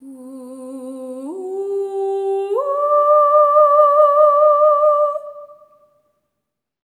ETHEREAL07-R.wav